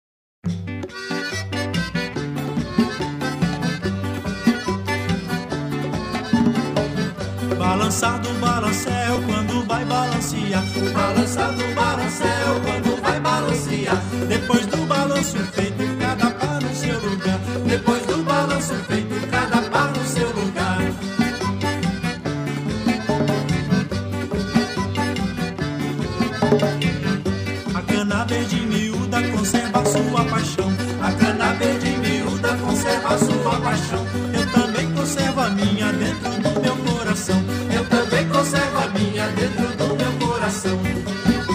Algumas cantigas da Ilha